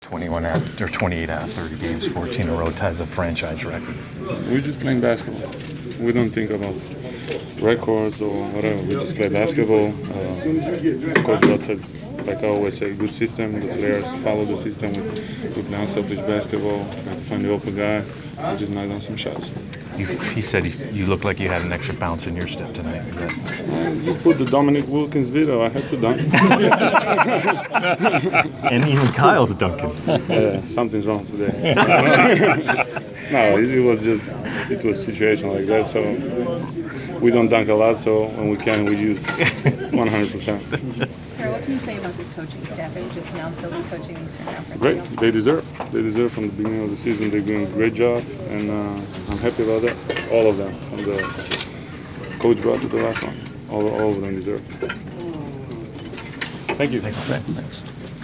Inside the Inquirer: Postgame interview with Atlanta Hawks’ Pero Antic (1/21/15)
We caught up with Atlanta Hawks’ forward Pero Antic following his team’s 110-91 win over the Indiana Pacers on Jan. 21.